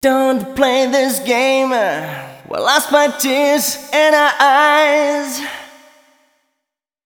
012 male.wav